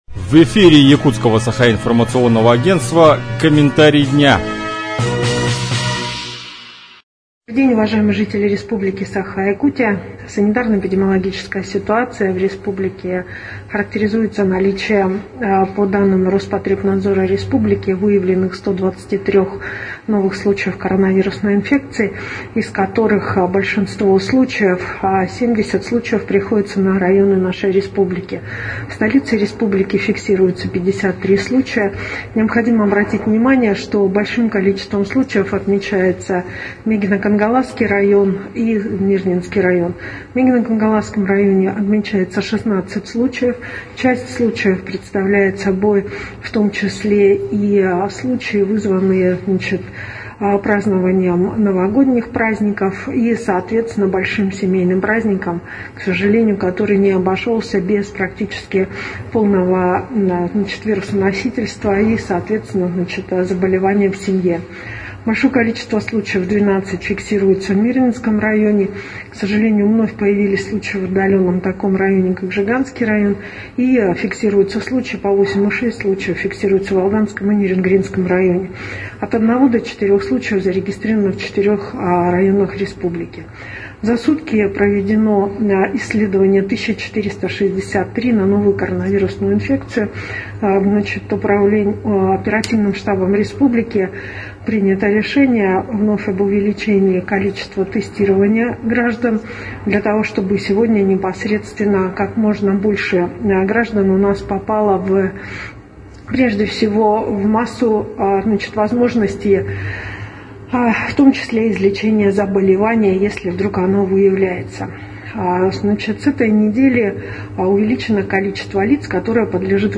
Об обстановке в Якутии на 12 января рассказала вице-премьер Якутии Ольга Балабкина.